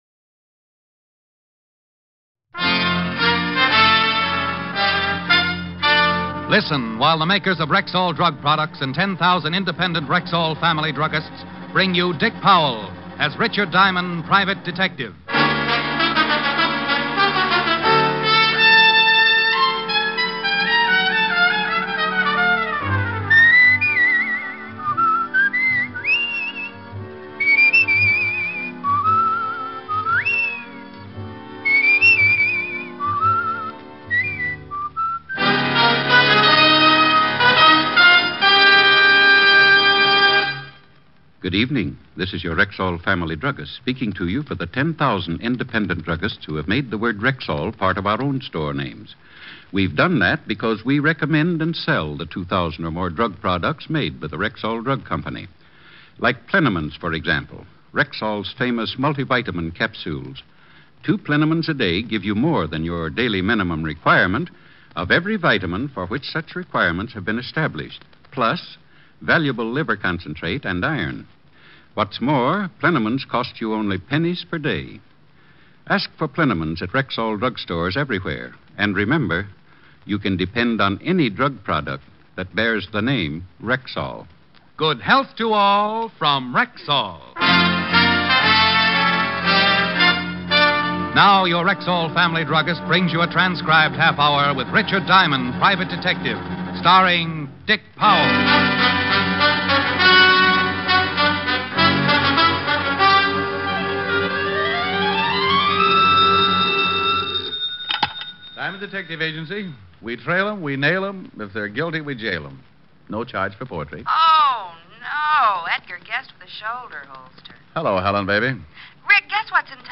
Dick Powell starred in the Richard Diamond, Private Detective radio series as a wisecracking, former police officer turned private detective.